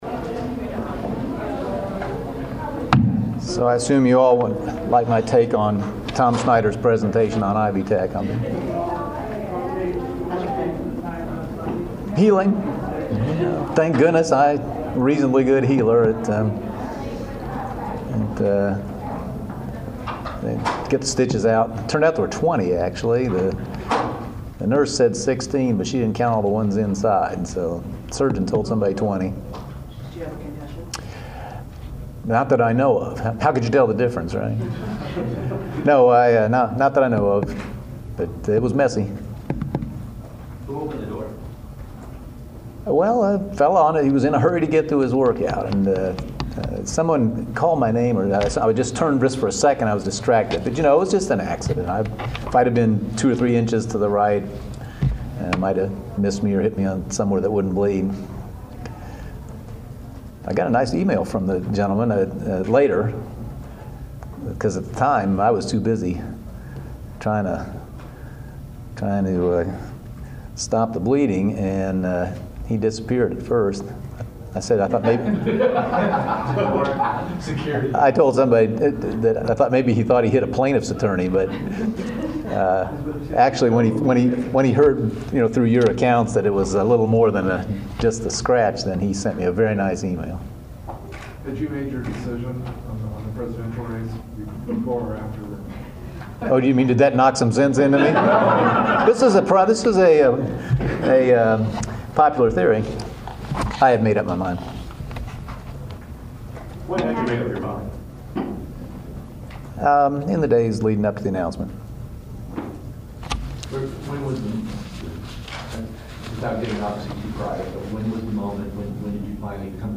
Indiana Governor Mitch Daniels spent some time with the news media today speaking on a number of issues; his decision to not run for the Presidency, the current crop of Presidential candidates, the recent Supreme Court ruling that there is no right to reasonably defend yourself from police if they enter your home illegally and a number of other state issues as well as his future and his recent injury.